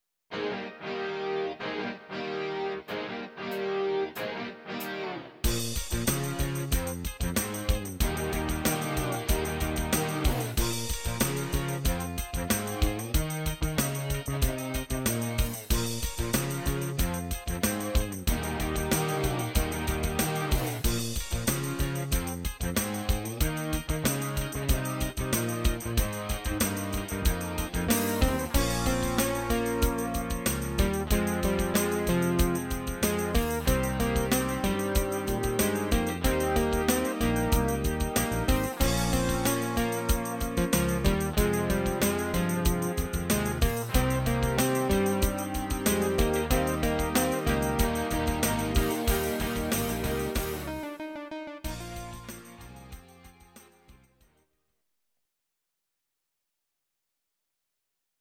Audio Recordings based on Midi-files
Pop, Rock, 2000s